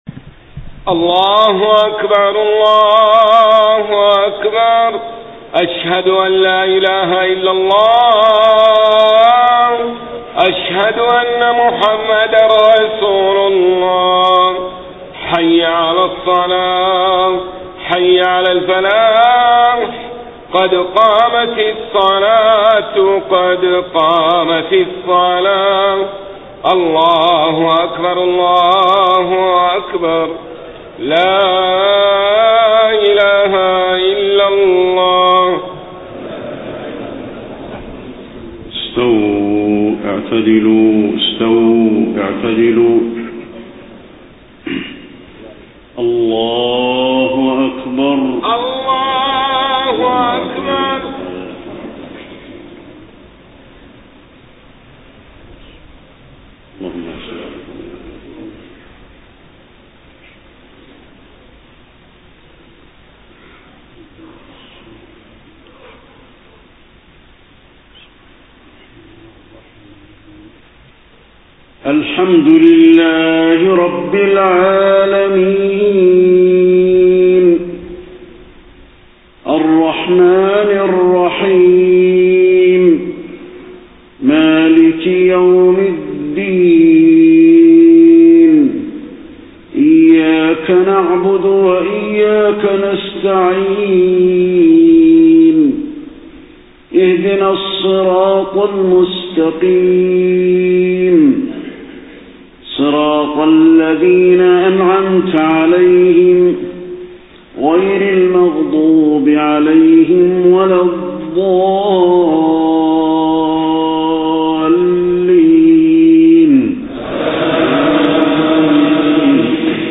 صلاة المغرب 24 صفر 1431هـ سورتي الطارق و القارعة > 1431 🕌 > الفروض - تلاوات الحرمين